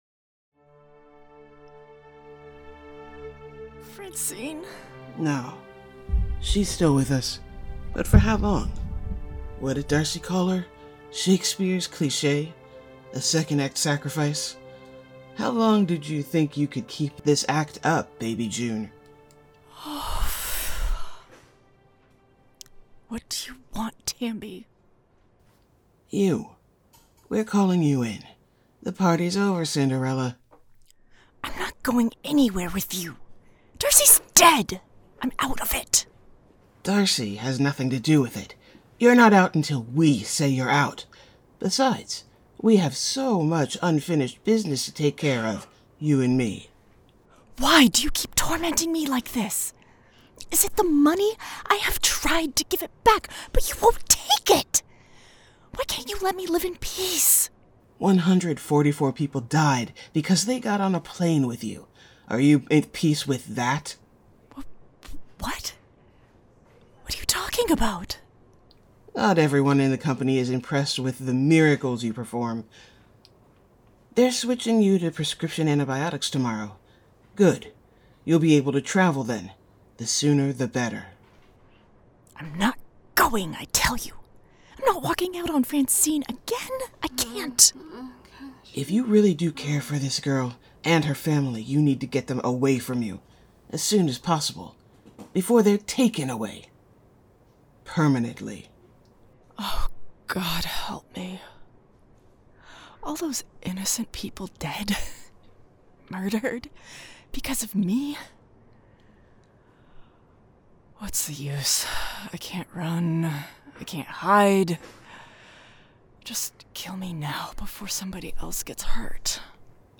Strangers In Paradise – The Audio Drama – Book 8 – My Other Life – Episode 6 – Two True Freaks